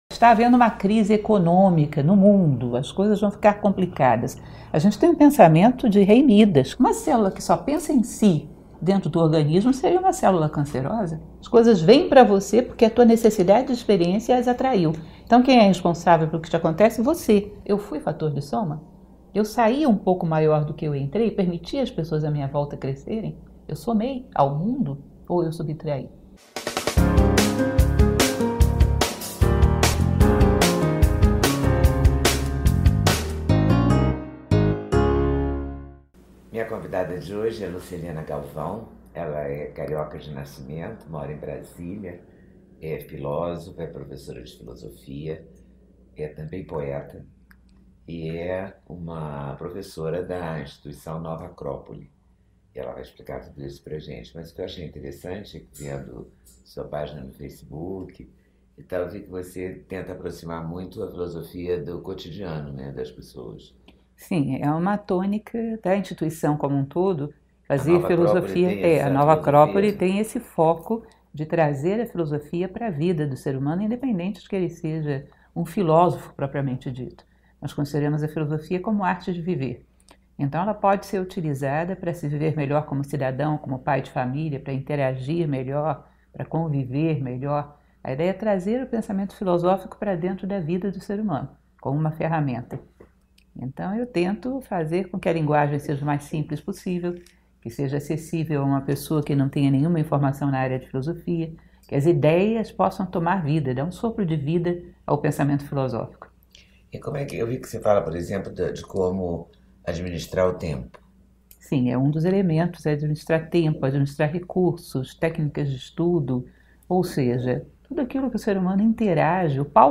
Nova Acrópole agradece à jornalista Leda Nagle pelo convite feito à professora Lúcia Helena Galvão para esta oportuna entrevista, sobre Crise, concedida em dezembro de 2017.